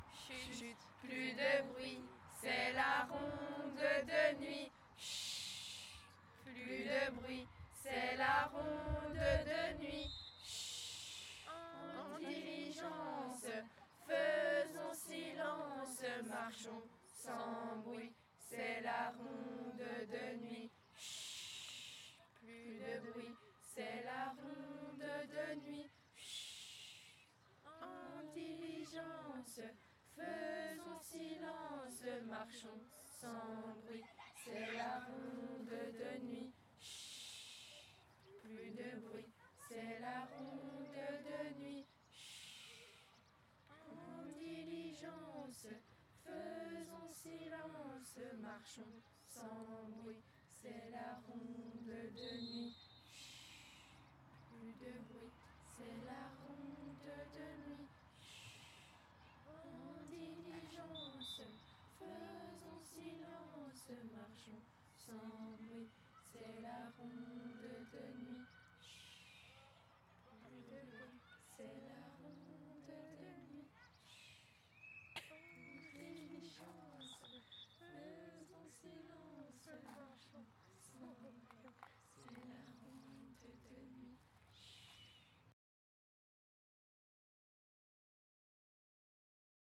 Genre : chant
Type : chant de mouvement de jeunesse
Interprète(s) : Patro de Pontaury
Lieu d'enregistrement : Florennes
Enregistrement réalisé dans le cadre de l'enquête Les mouvements de jeunesse en chansons.